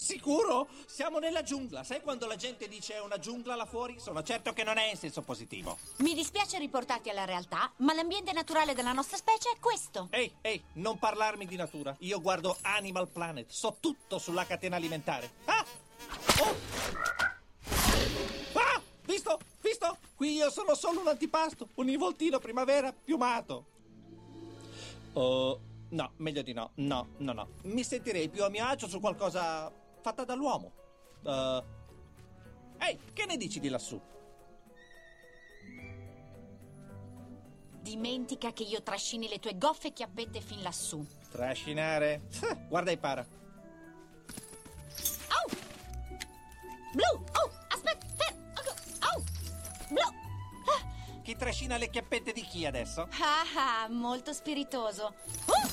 Fabio De Luigi nel film d'animazione "Rio", in cui doppia Blu.
• "Rio" e "Rio 2 - Missione Amazzonia" (Voce di Blu)